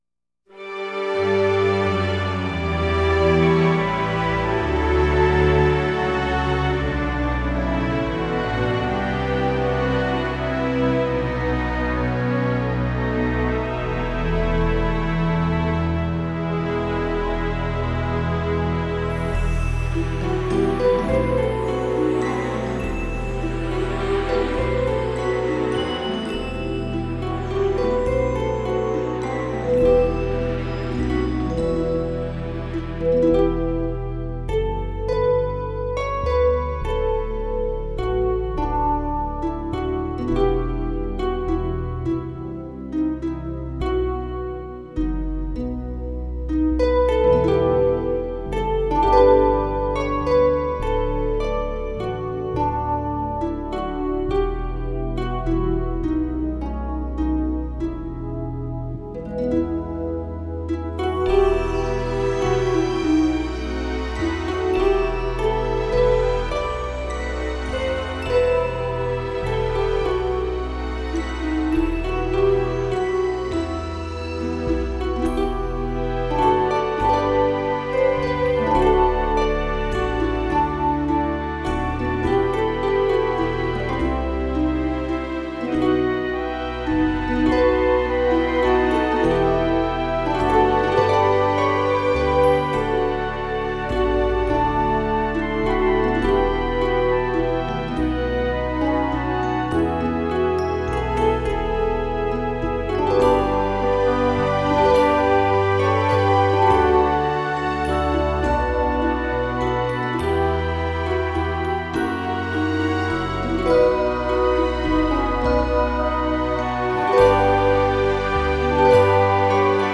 New Age